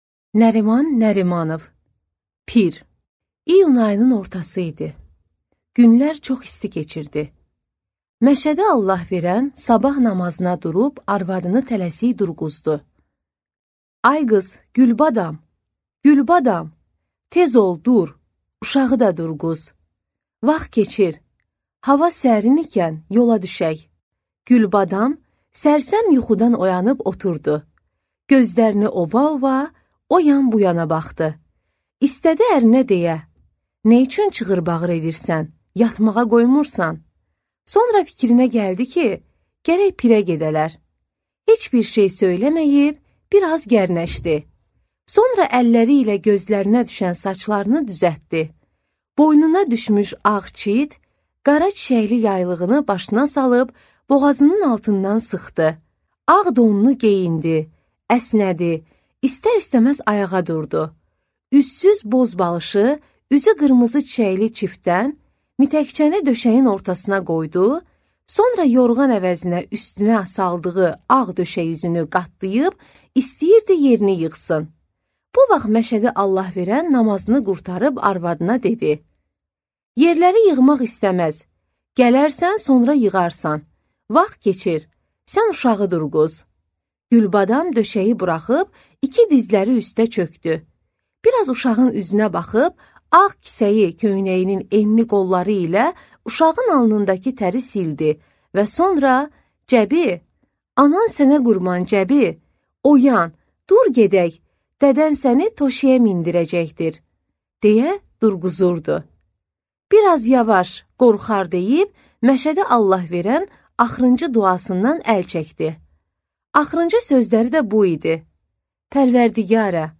Аудиокнига Pir | Библиотека аудиокниг